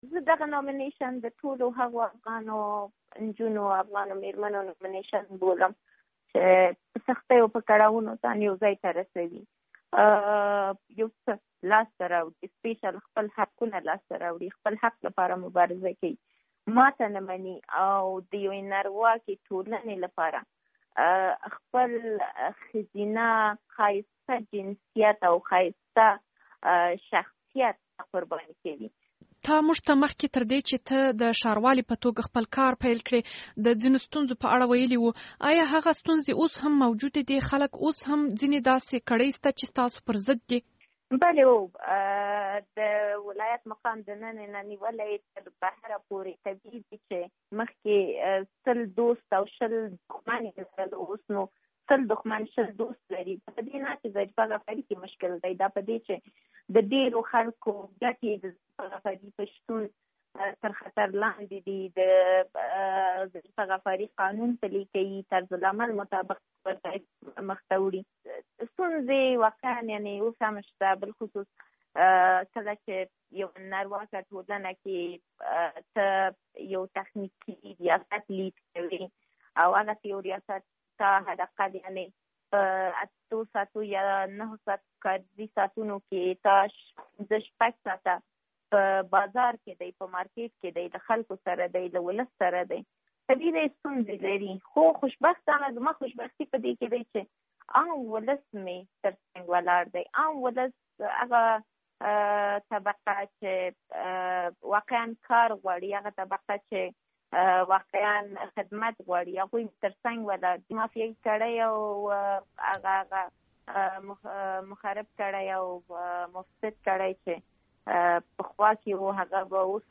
له اغلې غفاري سره مرکه